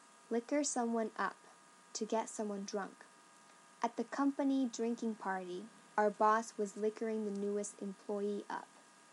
英語ネイティブによる発音はこちらは下記をクリックしてください。